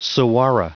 Prononciation du mot saguaro en anglais (fichier audio)
Prononciation du mot : saguaro